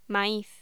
Locución: Maíz
voz